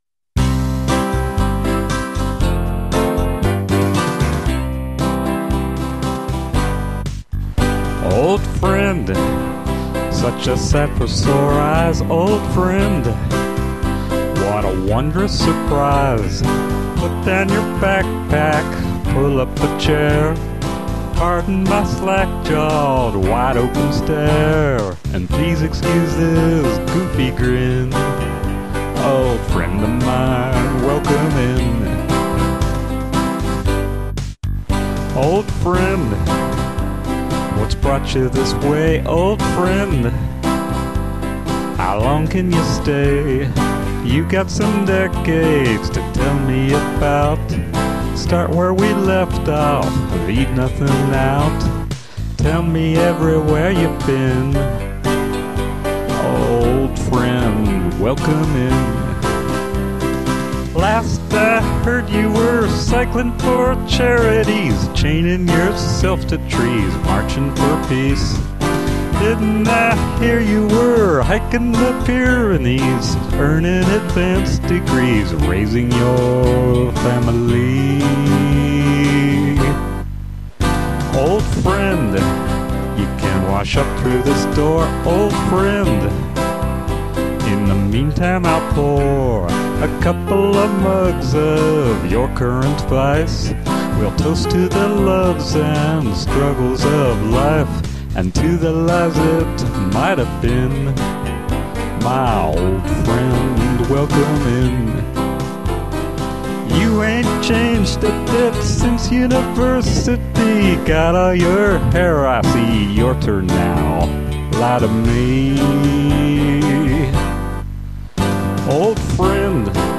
mid-tempo folk or country, male or female voice